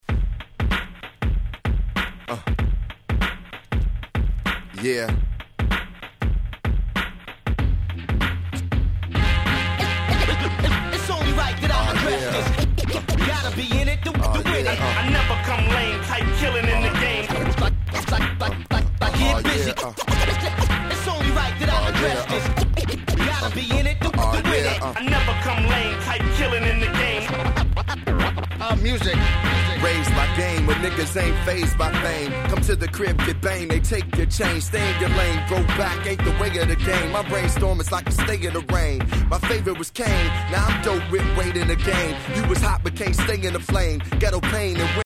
07' Hip Hop Classic !!